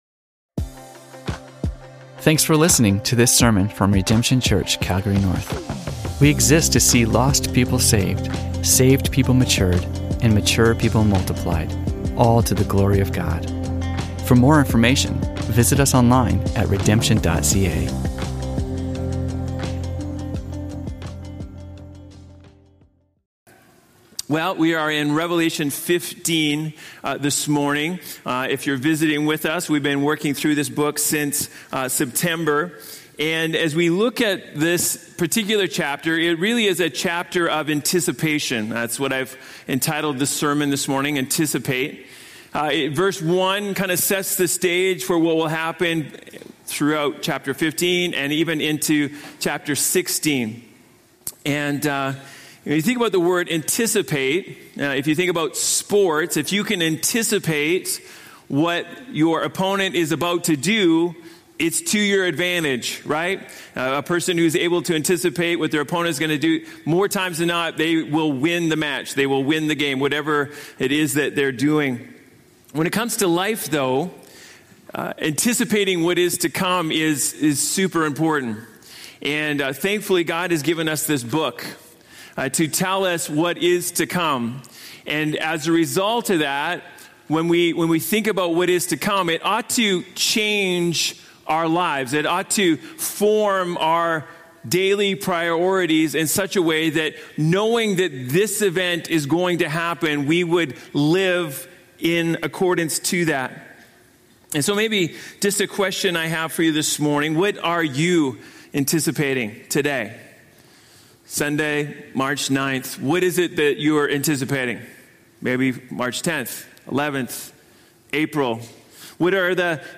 Broadcasted live from The Father's House, Calgary, Alberta.